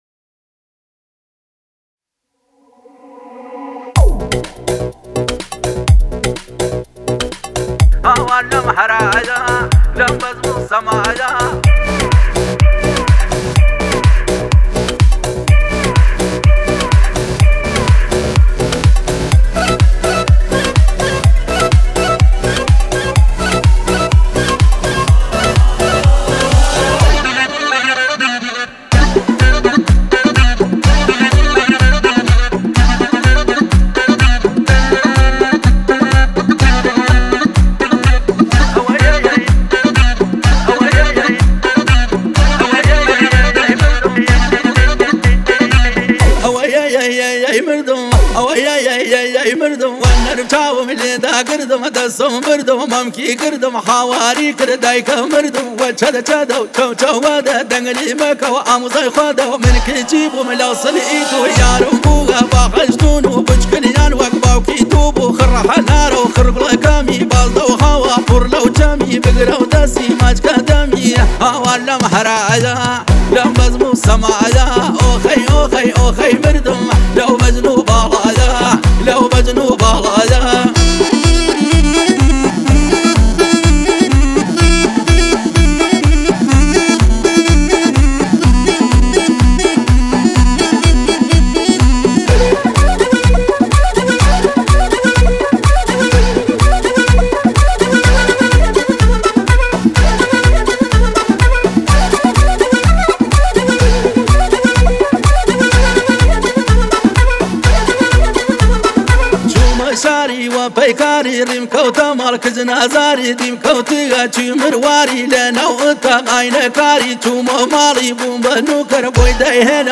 آهنگ کردی شاد آهنگ های پرطرفدار کردی